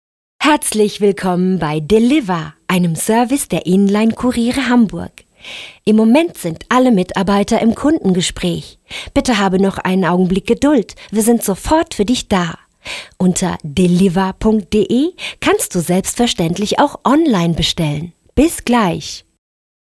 • junge, erfrischende und freundliche Stimme • besonders im Bereich Musik, Lifestyle einsetzbar • umfangreiches Klang- & Volumenspektrum • wandelbar bzw. vielseitig, süß, sanft, dynamisch, warm, sexy, lustig
Sprechprobe: Werbung (Muttersprache):